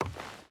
Wood Walk 2.ogg